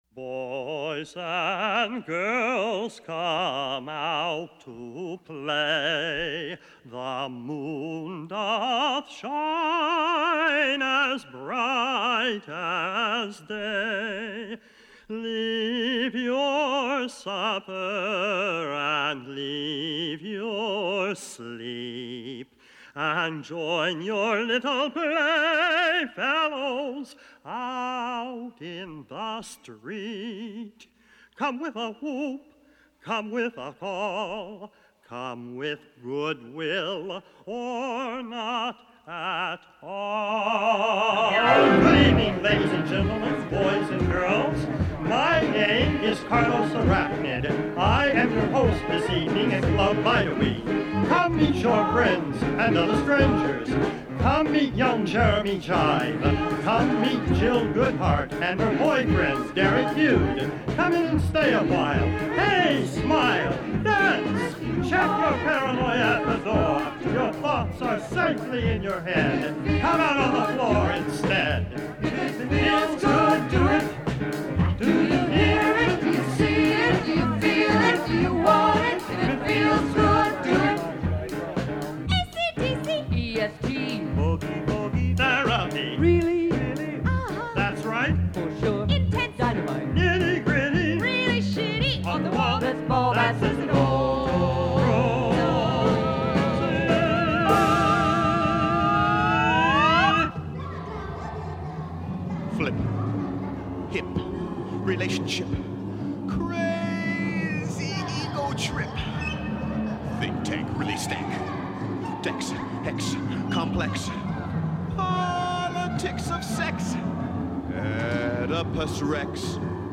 a lively and biting musical satire